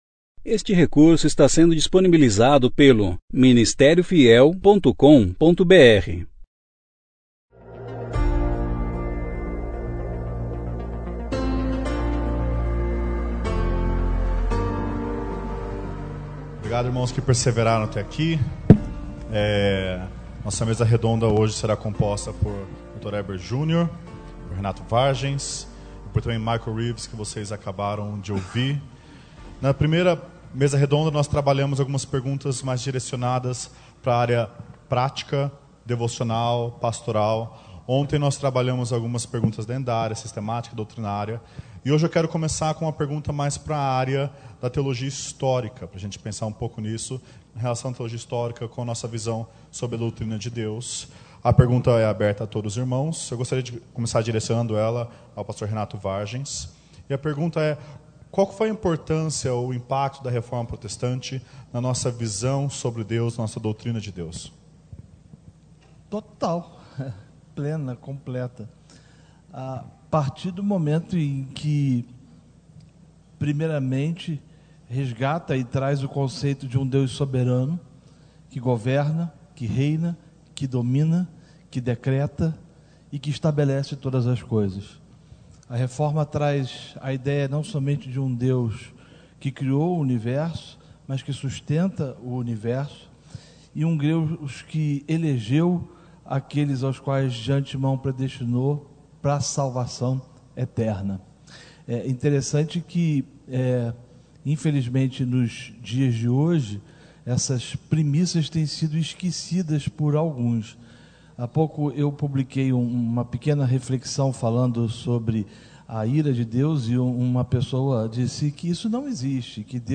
Mesa redonda 3
Conferência: 35ª Conferência Fiel para Pastores e Líderes Tema: Deus Supremo Ano